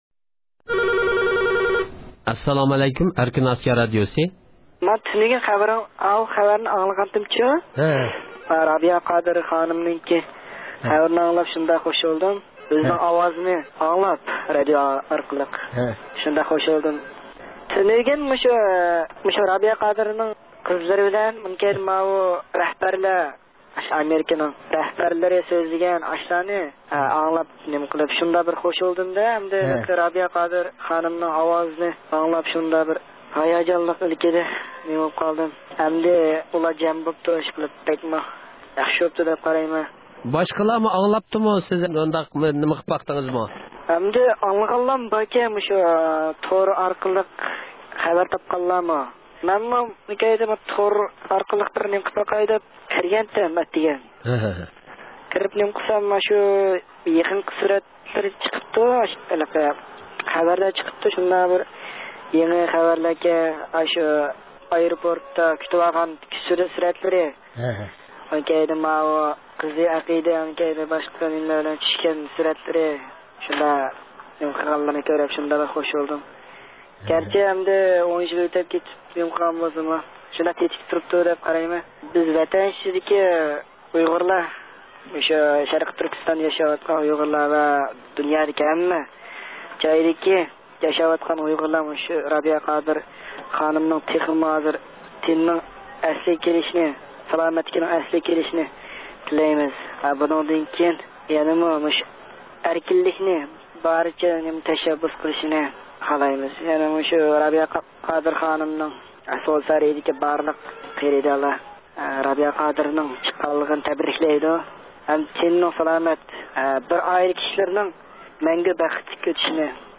ئىستانسىمىزنىڭ ھەقسىز لىنىيىسىگە تېلېفون ئۇرغان بىر ياش، رابىيە قادىر خانىمنىڭ ئەركىنلىككە چىققانلىقىنى تەبرىكلەپ، ئۆزلىرىنىڭ بۇنىڭدىن ئىنتايىن ھاياجانلانغانلىقىنى بىلدۈردى.